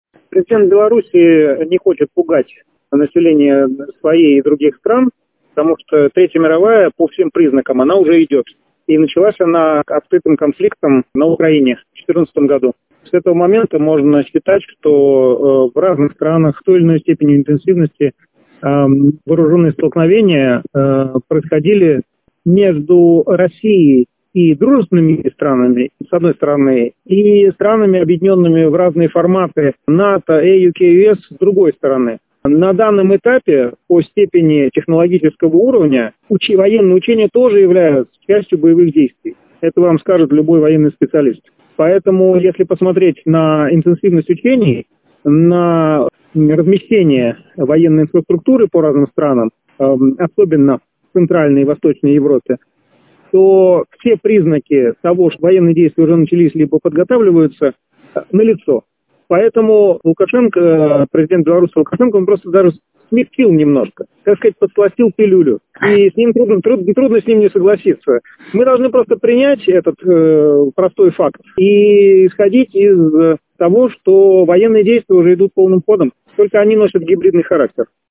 ГЛАВНАЯ > Актуальное интервью